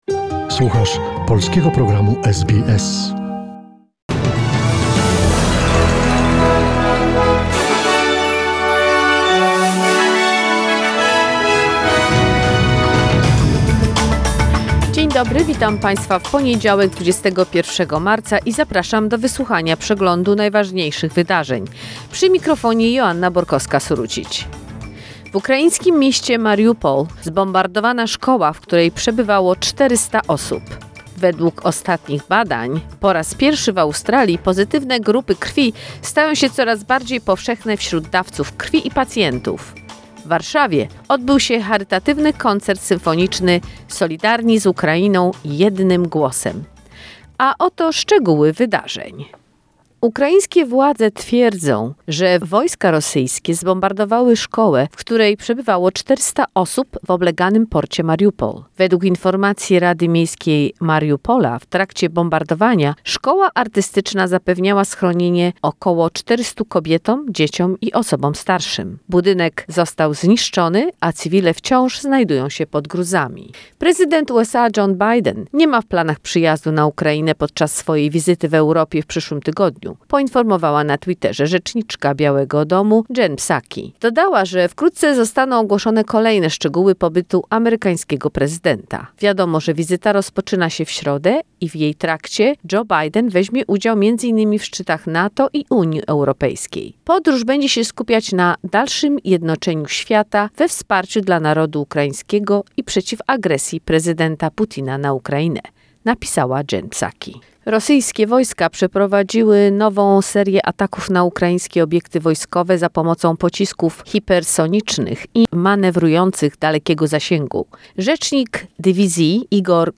SBS News in Polish, 21 March 2022